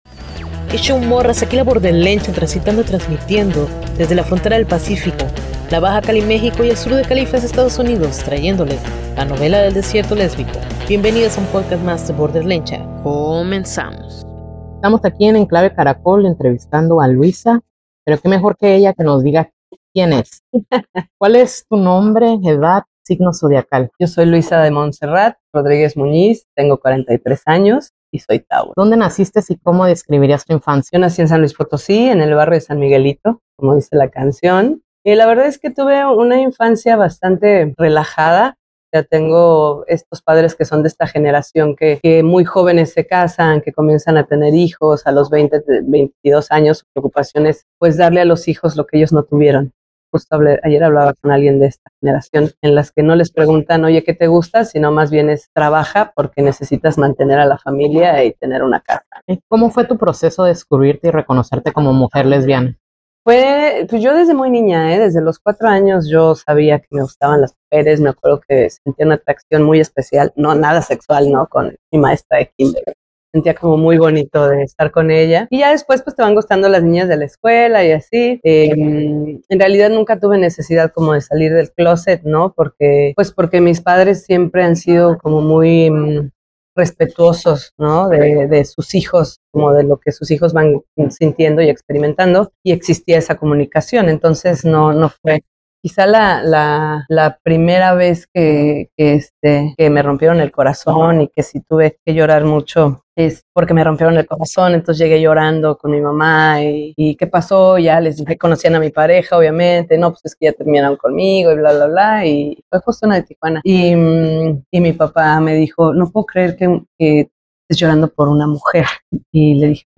En esta ocasión entrevistamos